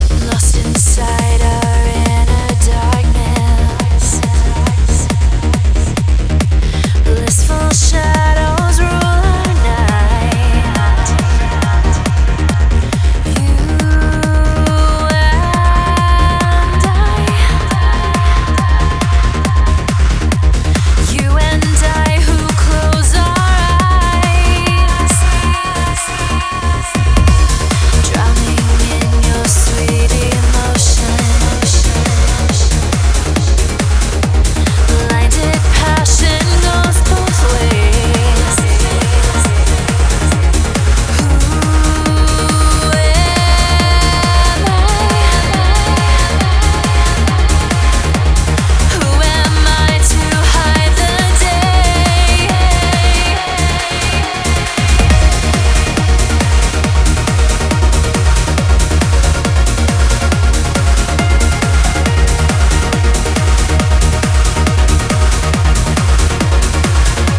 • Качество: 320, Stereo
club
Trance
vocal